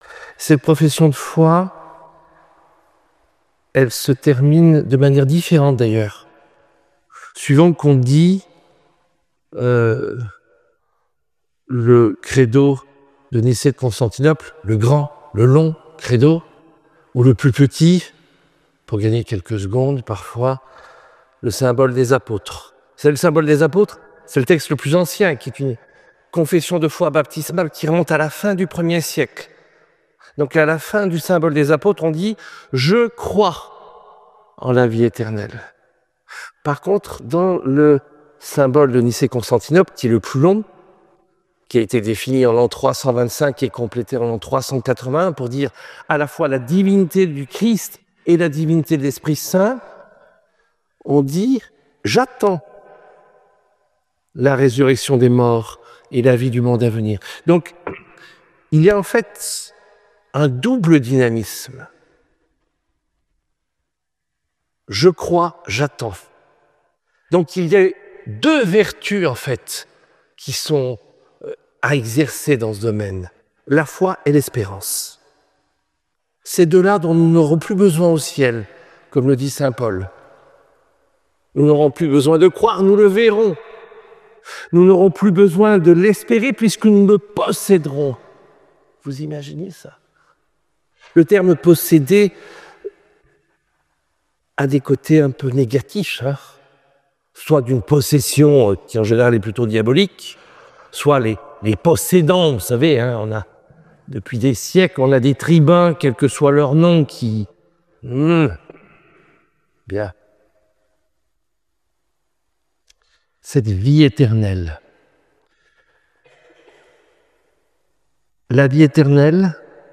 Conférence de la semaine